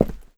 step8.wav